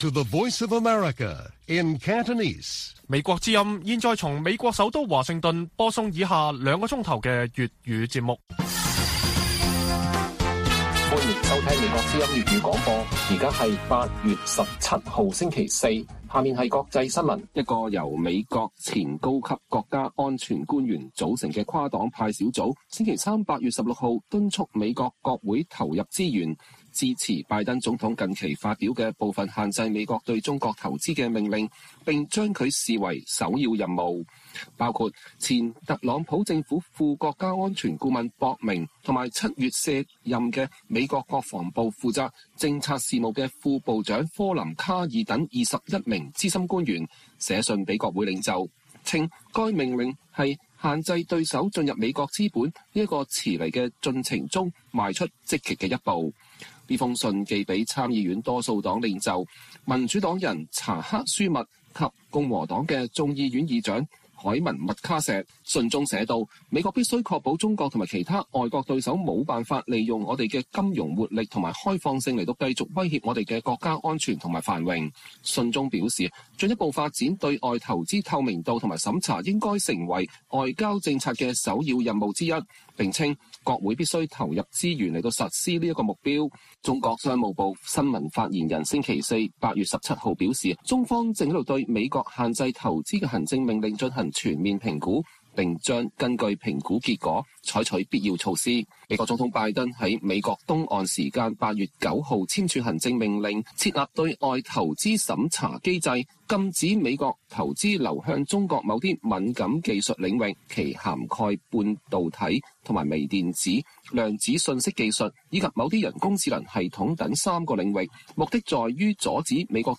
粵語新聞 晚上9-10點： 台灣副總統賴清德結束巴拉圭之行抵達三藩市過境